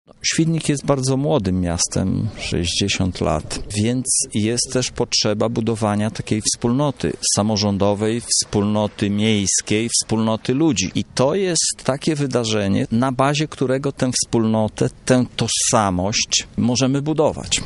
Dziś w Świdniku odbyły się uroczystości upamiętniające te wydarzenia.